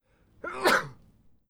sneeze-single-a.wav